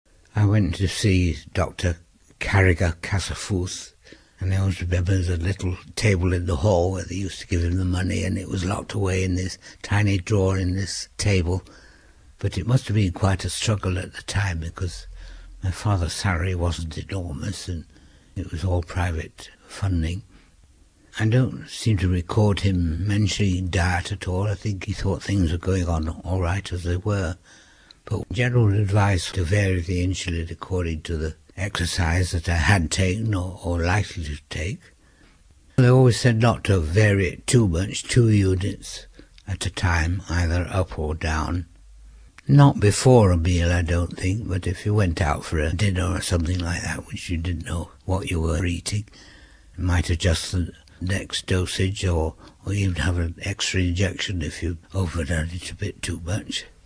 Interview 28